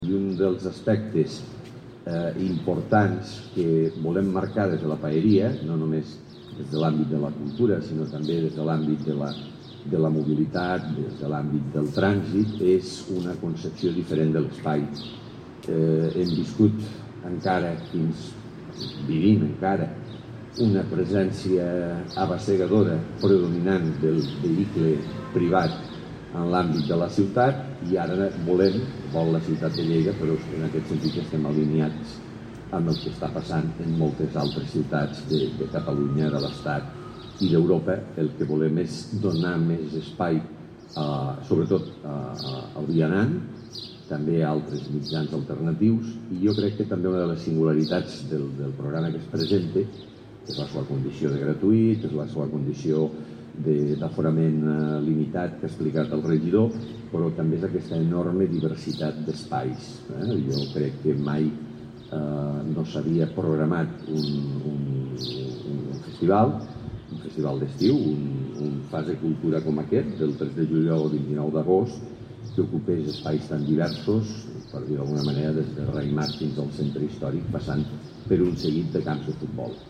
tall-de-veu-del-paer-en-cap-miquel-pueyo-sobre-la-iniciativa-fase-cultura